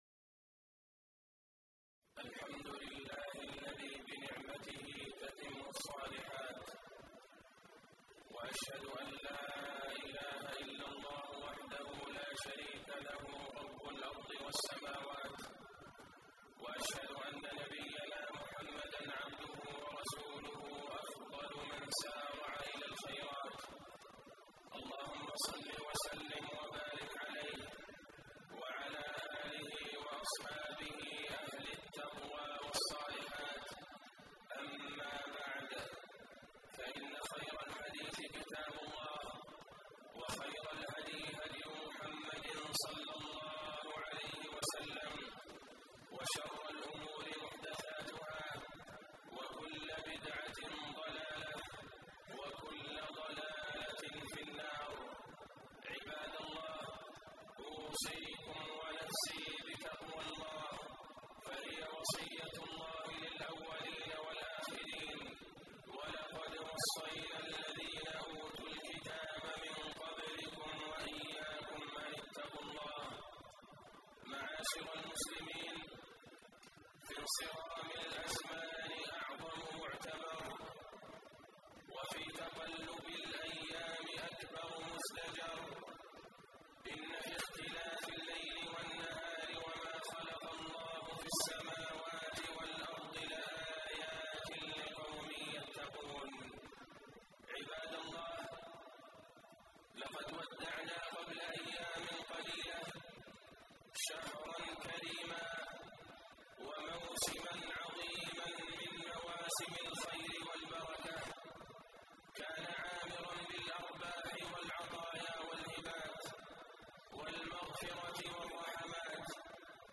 تاريخ النشر ٦ شوال ١٤٣٨ هـ المكان: المسجد النبوي الشيخ: فضيلة الشيخ د. عبدالله بن عبدالرحمن البعيجان فضيلة الشيخ د. عبدالله بن عبدالرحمن البعيجان المداومة على العبادة بعد رمضان